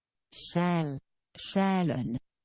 File File history File usage Samska_sjael.ogg (file size: 25 KB, MIME type: application/ogg ) Prono guide for Samska själ File history Click on a date/time to view the file as it appeared at that time.